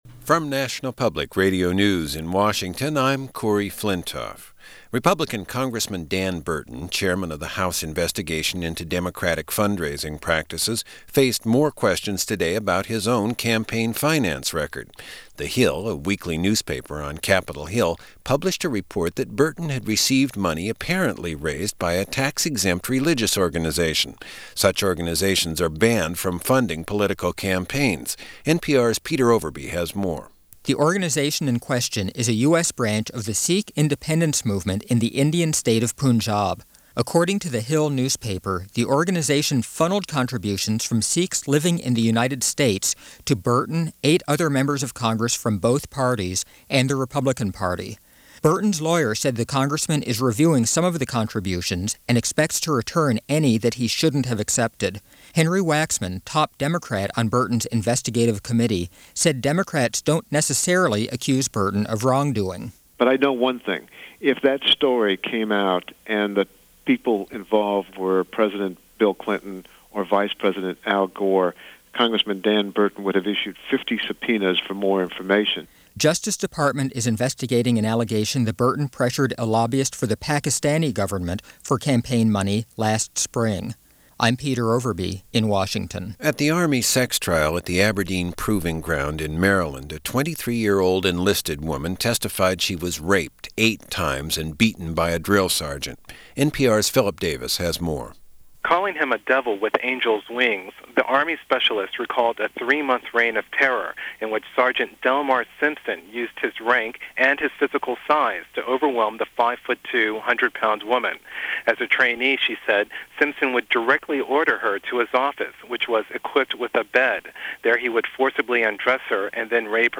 April 16, 1997 - Scandals And Peace Talks - Big Tobacco And Two Koreas - news of the day from NPS's All Things Considered.